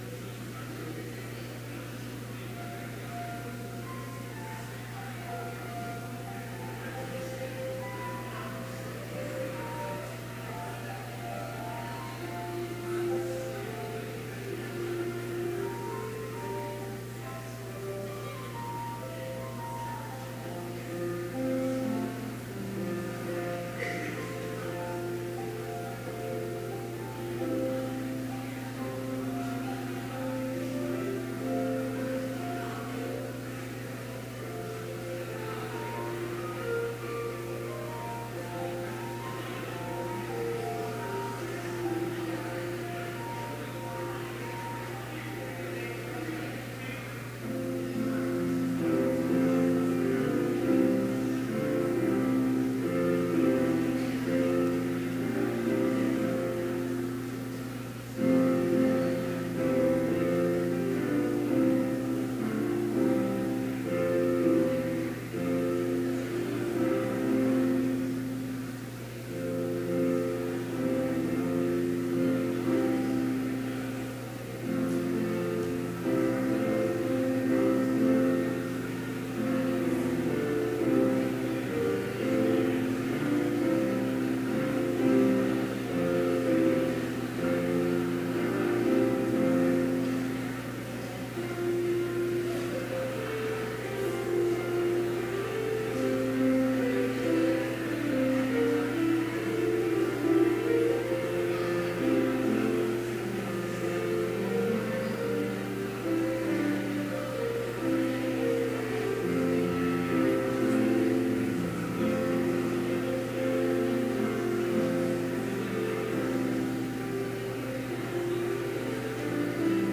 Complete service audio for Chapel - October 20, 2017